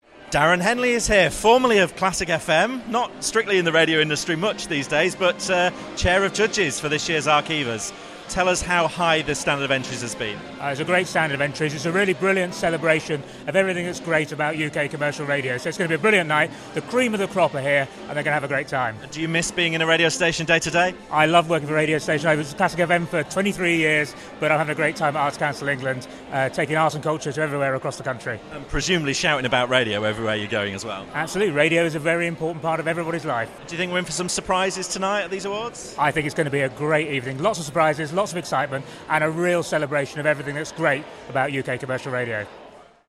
Arqiva Commercial Radio Awards 2016